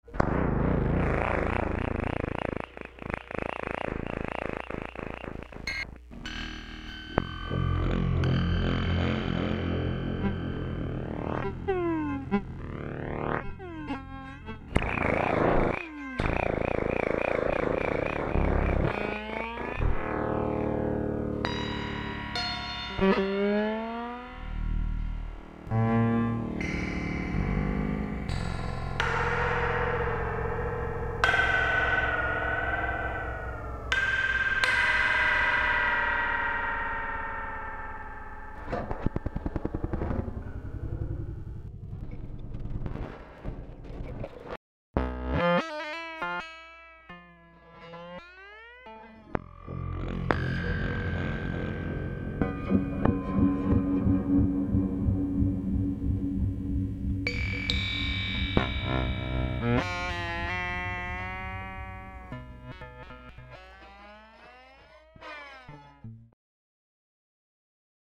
VERY LONG STRINGS
One reason is that for very long strings the fundamental is likely to be subsonic, and what’s to be heard in the hearing range is a sonic landscape crowded with closely spaced overtones.
I did a lot of crazy things with these strings, most with the aid of magnetic pickups. Some involved slides, springs, rattling bridges, dampers, weights and what not. Since this was not to be a permanent set-up, I sampled the sounds for later use.
09-022_LongStrings.mp3